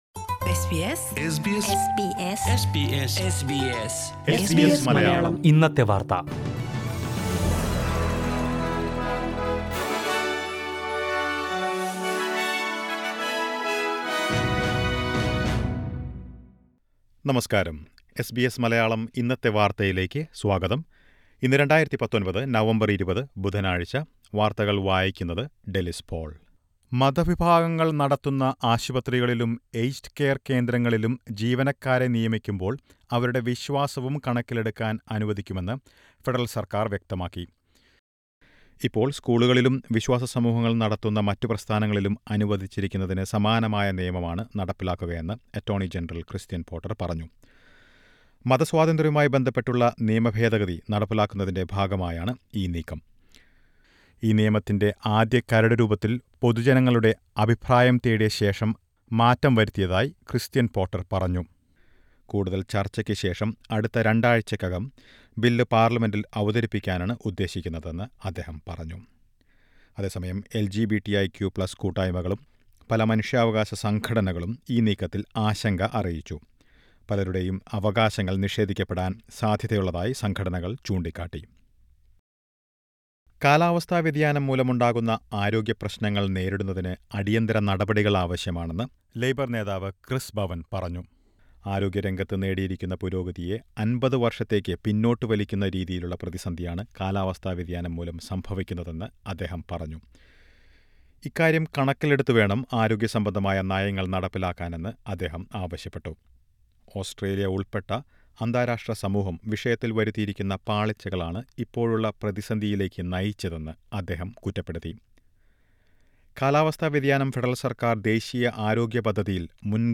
2019 നവംബർ 20ലെ ഓസ്ട്രേലിയയിലെ ഏറ്റവും പ്രധാന വാർത്തകൾ കേൾക്കാം…
news_new_2011.mp3